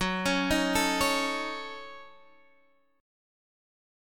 F#m6 chord